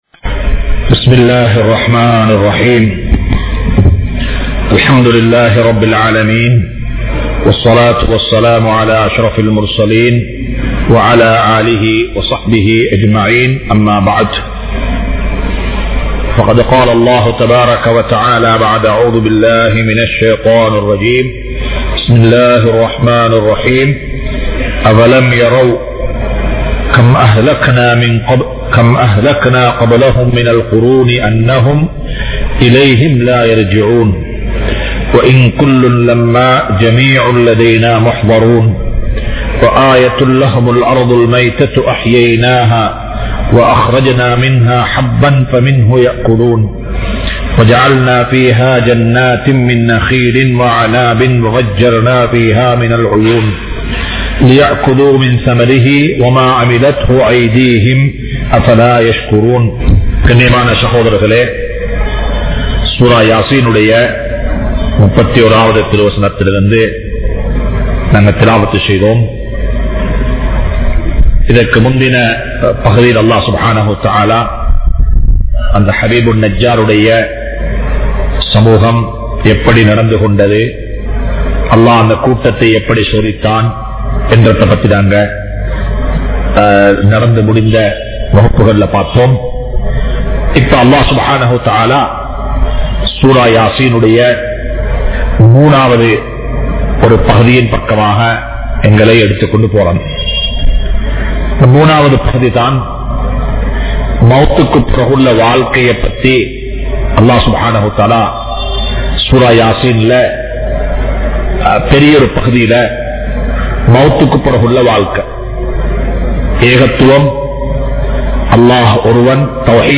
Majma Ul Khairah Jumua Masjith (Nimal Road)